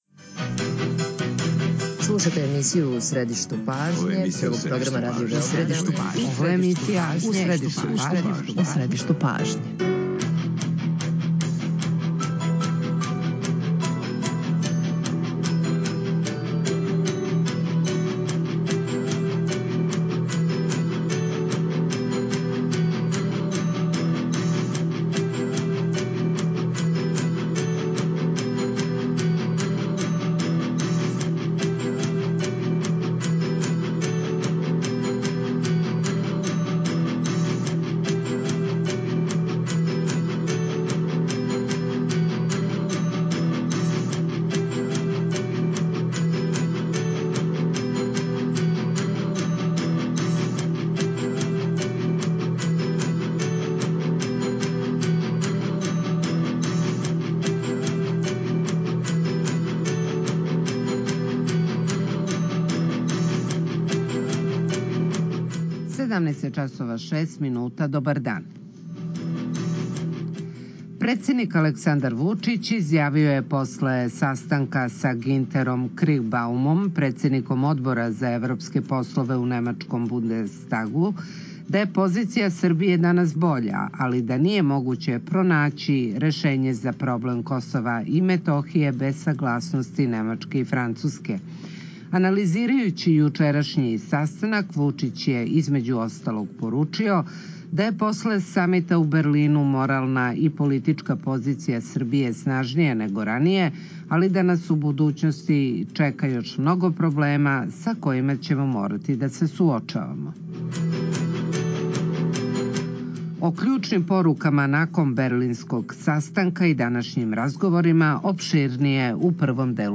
Гошћа је Катарина Обрадовић Јовановић, помоћник министра привреде.